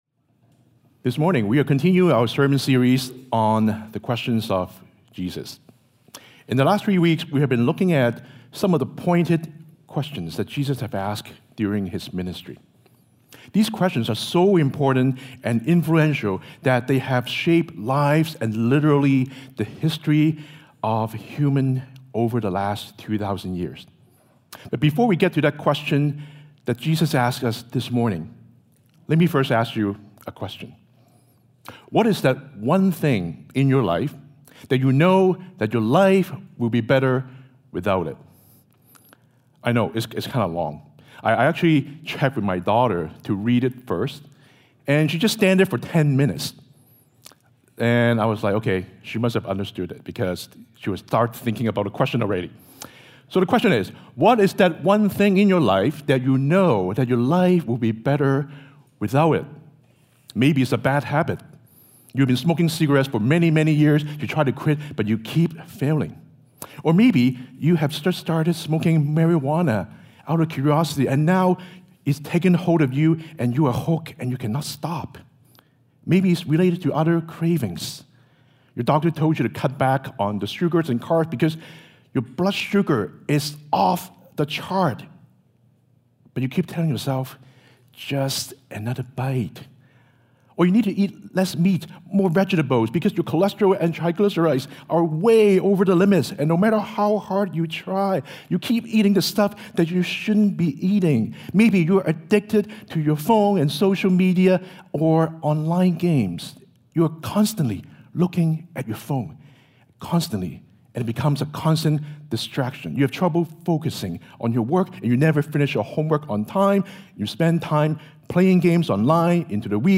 Sermons | Koinonia Evangelical Church (English - NEW duplicate)